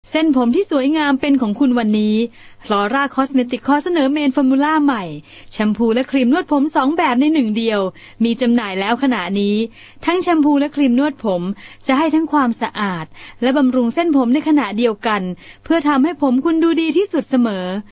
Sprecherin thailändisch für TV/Rundfunk/Industrie.
Sprechprobe: Werbung (Muttersprache):
Professionell thai female voice over artist